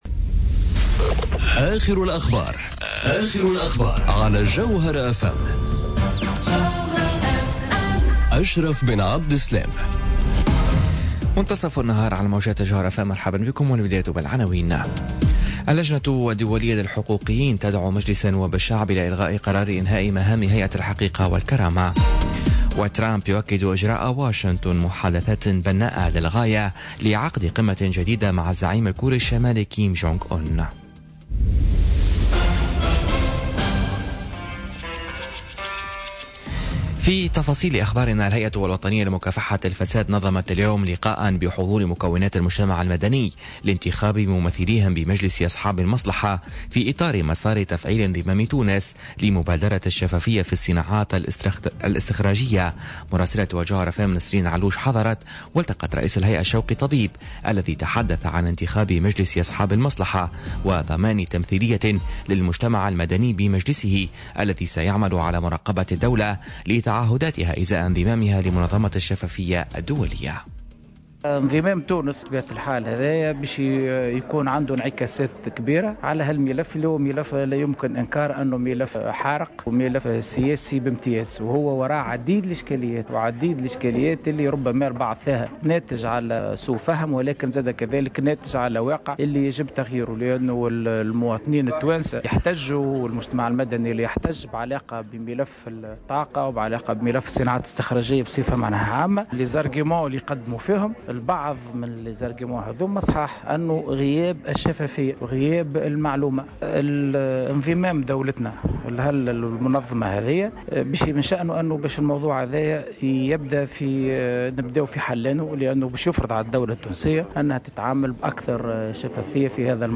نشرة أخبار منتصف النهار ليوم السبت 26 ماي 2018